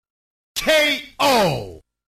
game_ko.mp3